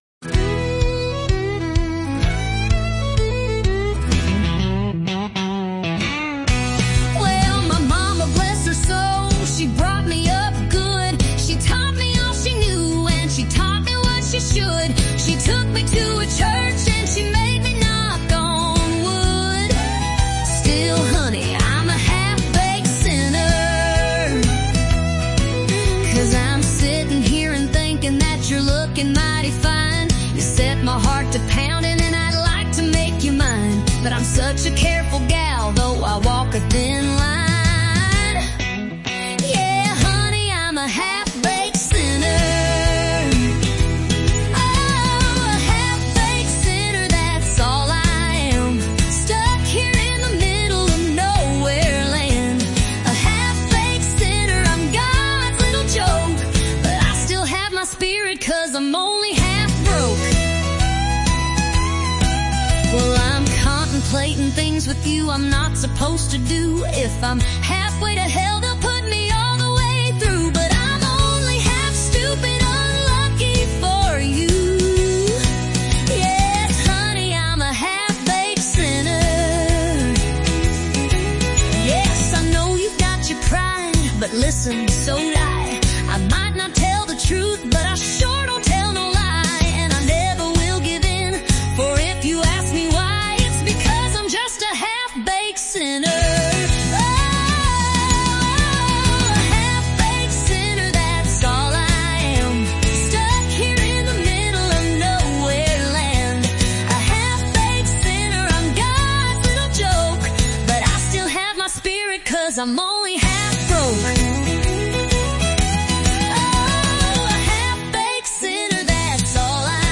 country flavor!